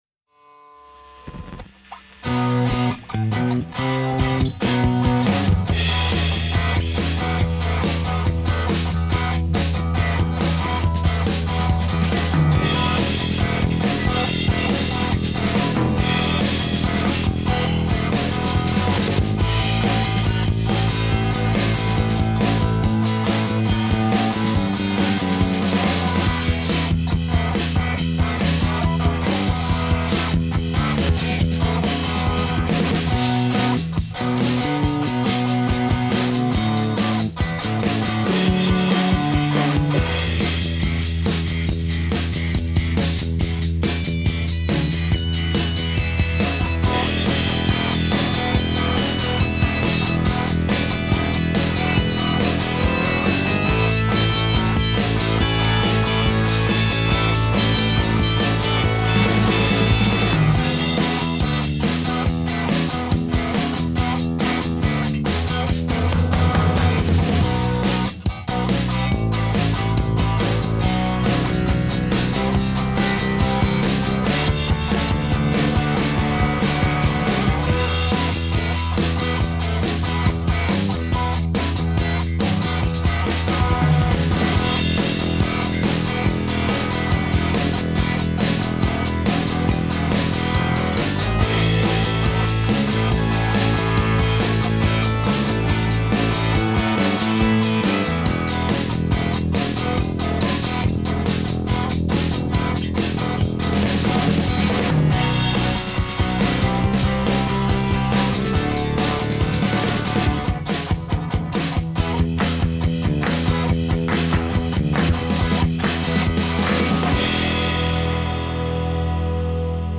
surf-rock song, old school style- instrumental
Still need to add lead guitar and maybe vocals.